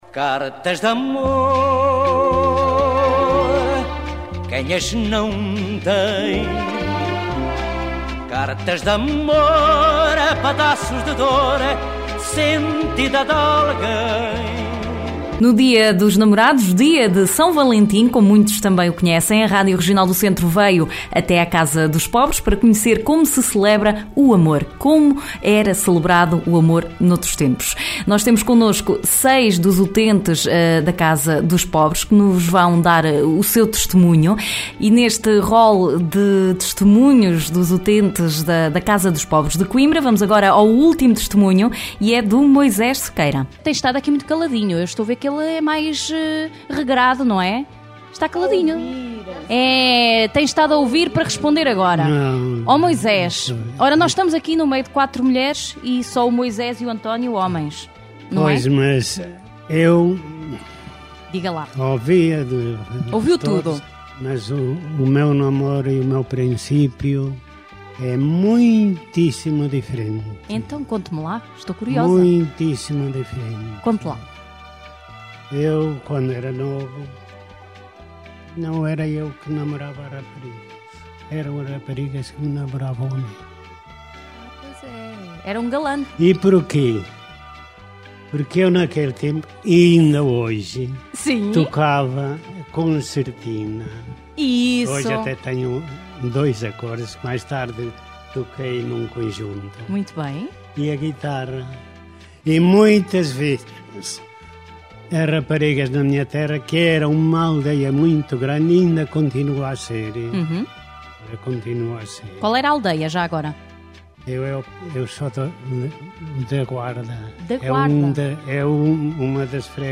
A Regional do Centro foi à Casa dos Pobres, em Coimbra, para saber junto de alguns utentes como se celebrava o amor há algumas décadas atrás. Oiça aqui um dos testemunhos.